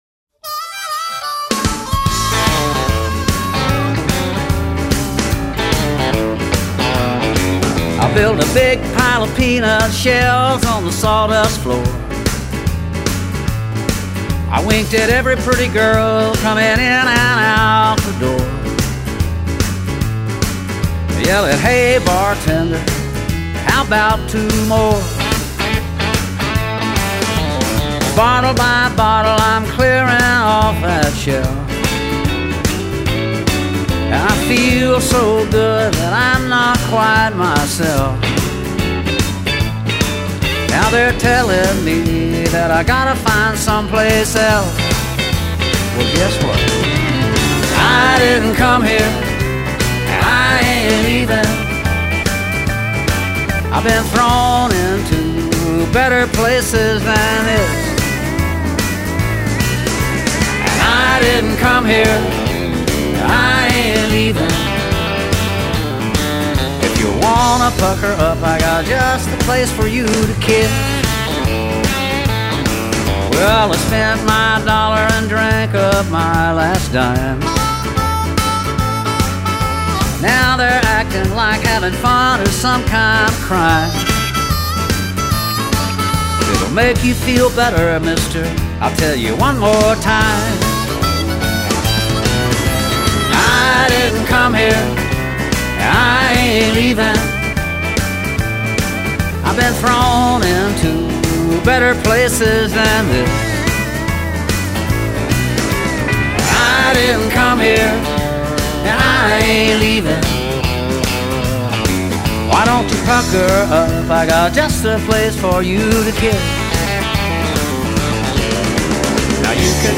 专辑类别：Country
耐聽的鄉村歌曲,輕鬆的旋律,開啟了一天愉快的心情,感謝!
很温暖的声线,谢谢